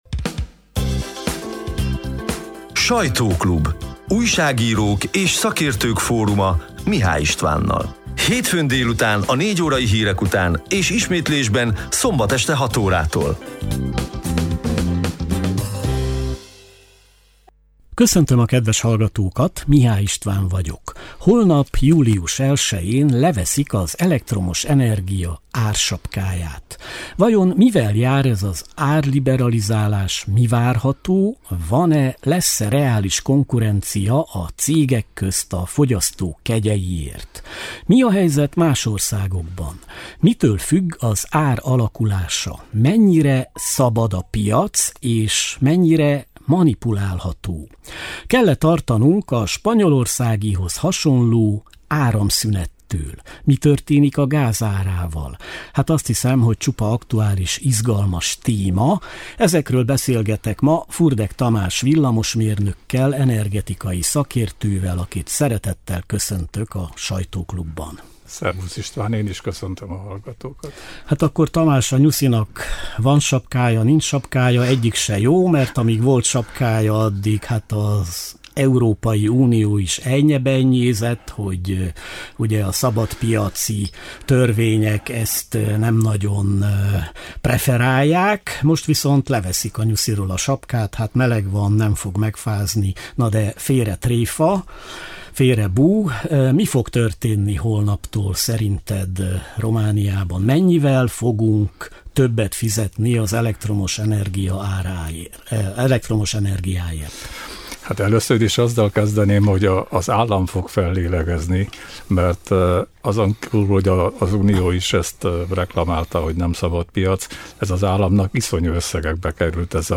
Érdemes meghallgatni a június 30-i, hétfő délutáni 55 perces élő műsor szerkesztett, kissé rövidített változatát, a lejátszóra kattintva.